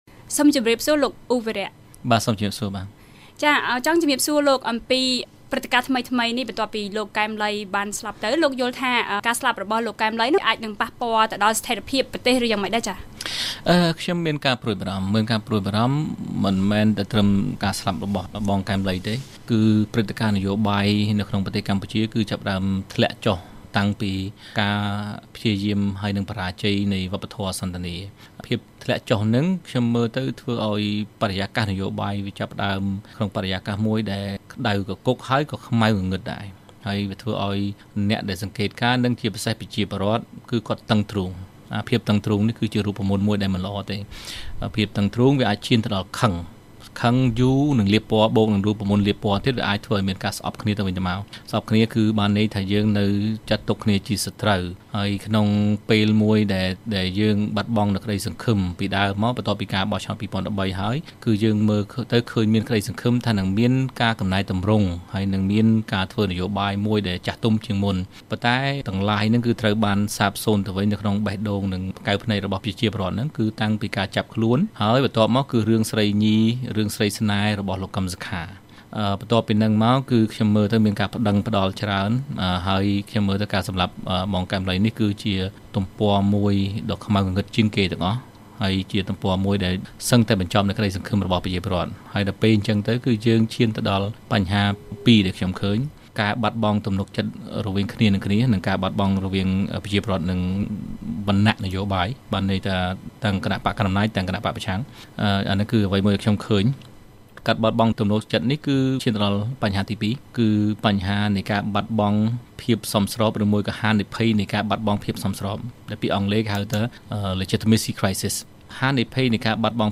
បទសម្ភាសន៍ VOA៖ ឃាតកម្មលោក កែម ឡី នាំឲ្យវិបត្តិនយោបាយនៅកម្ពុជាកាន់តែធ្ងន់ធ្ងរ